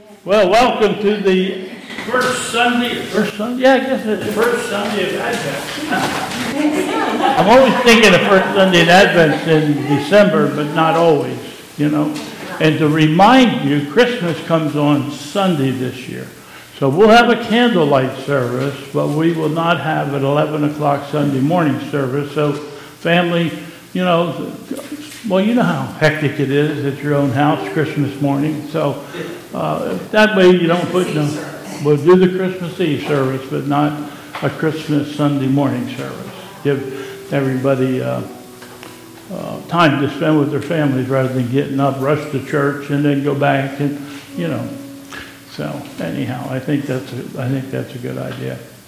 Chrismon Service
Prelude: Sing Alleluia to the Lord